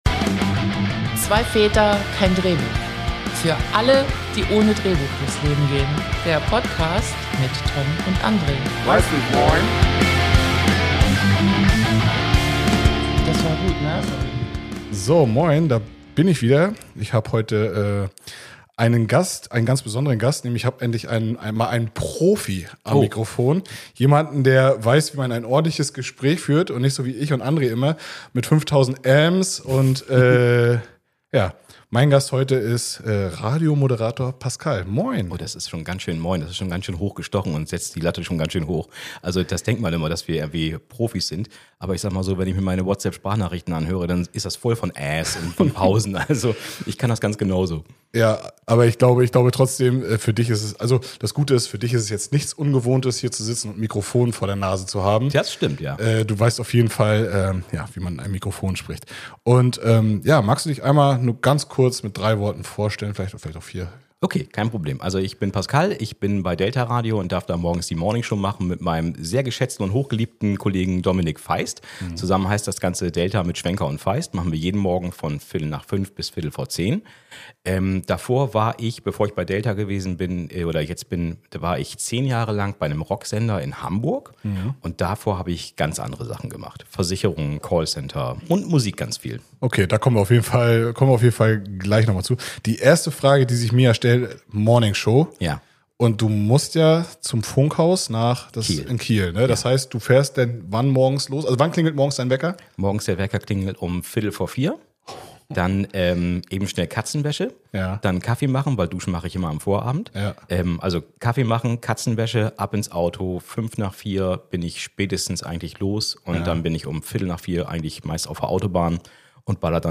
Eine ehrliche, humorvolle Folge über Leidenschaft, Disziplin und die Liebe zum Radio.